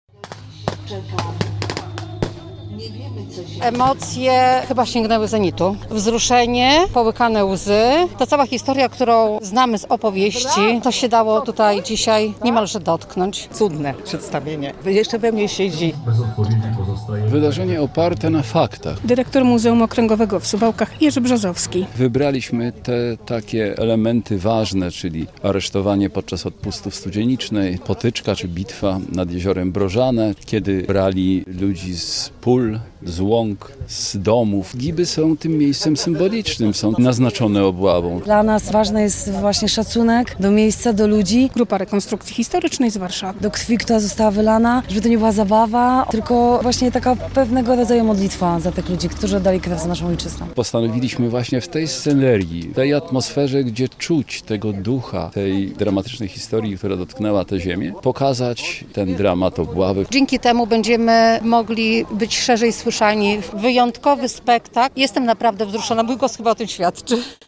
Rekonstrukcja Obławy Augustowskiej - relacja